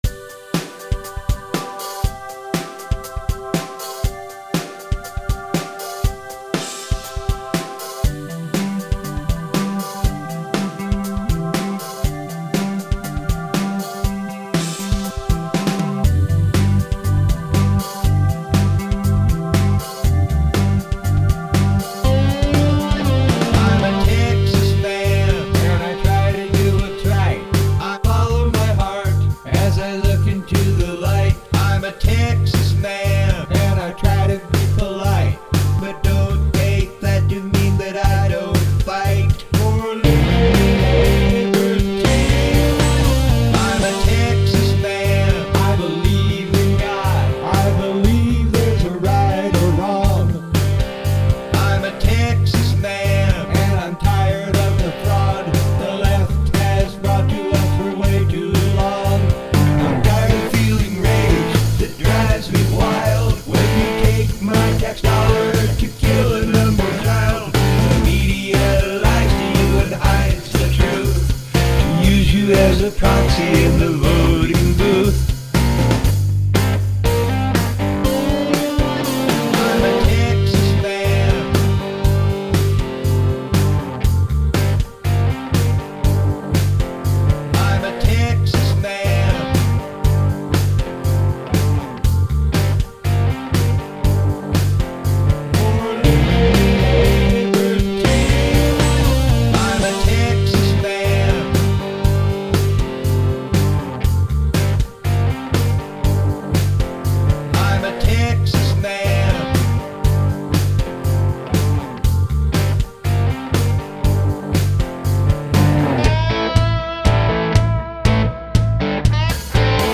Guitar
Drums